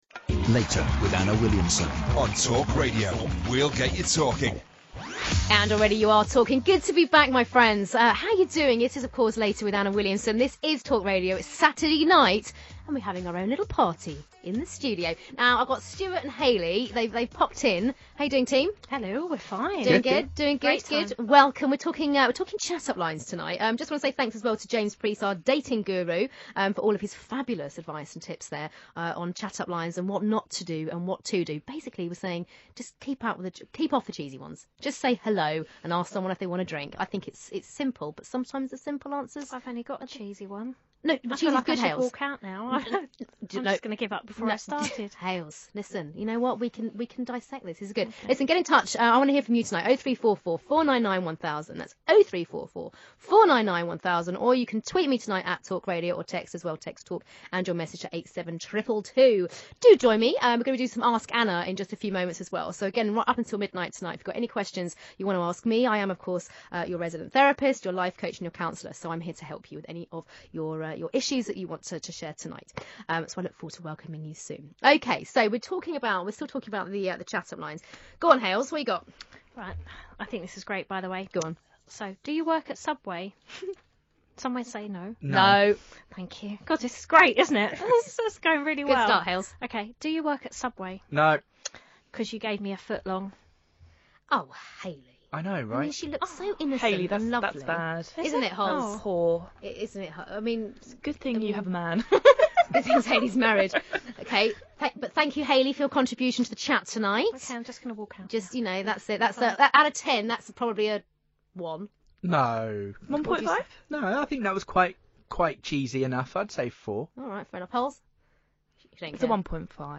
TalkRadio Sex and relationship show with Anna Williamson